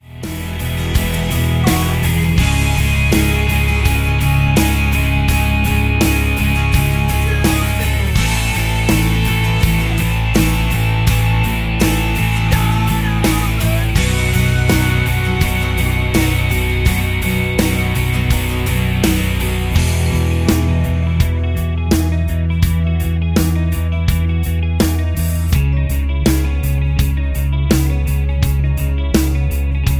MPEG 1 Layer 3 (Stereo)
Backing track Karaoke
Pop, Rock, 2000s